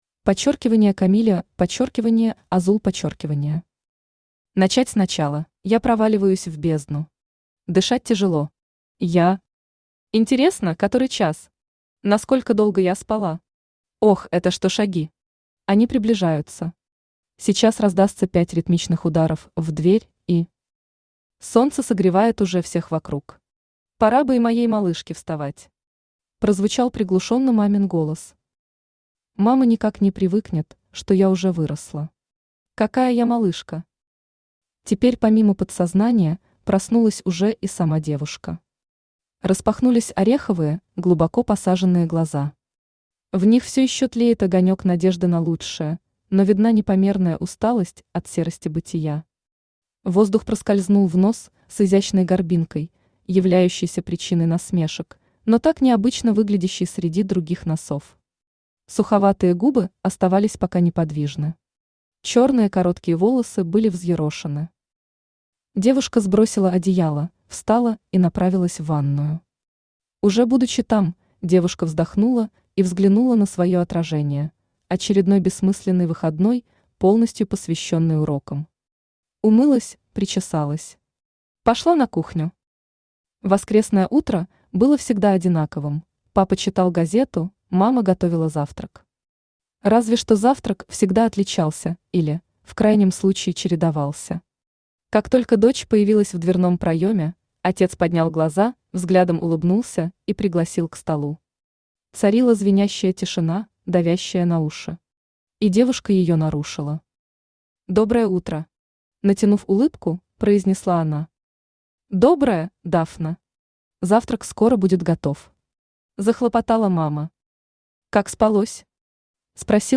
Аудиокнига Начать с начала